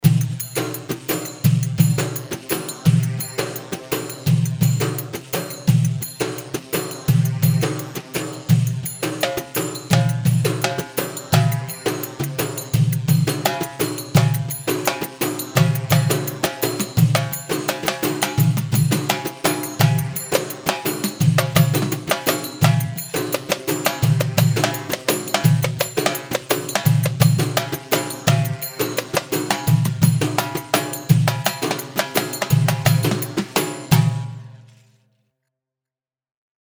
Basta 4/4 170 بستة
Basta-4-4-170-S.mp3